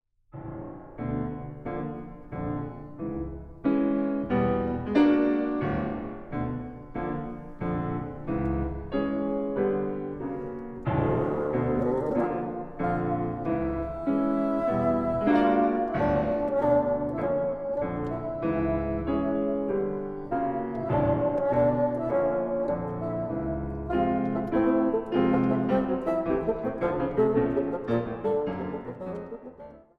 Klassieke muziek
Instrumentaal | Fagot